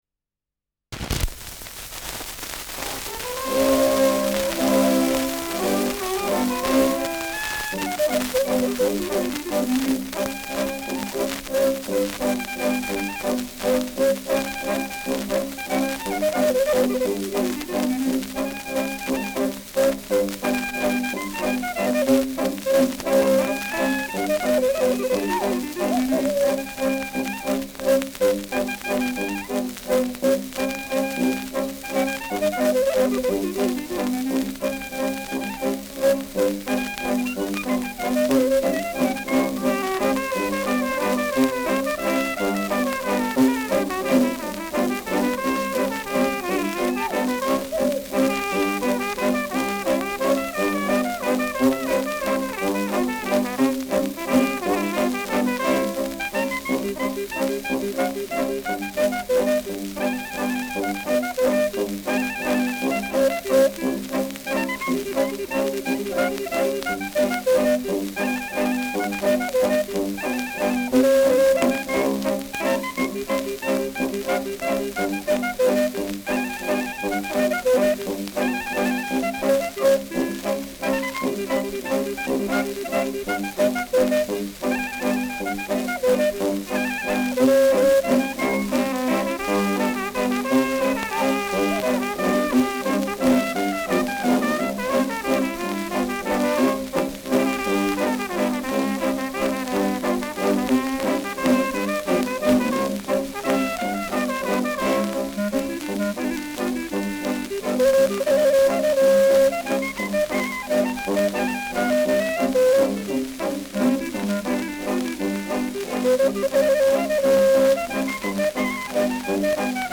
Schellackplatte
Abgespielt : Erhöhtes Grundrauschen : Vereinzelt leichtes Knacken : Schwingender Pfeifton im Hintergrund
Waldler-Kapelle Strassmeier, München (Interpretation)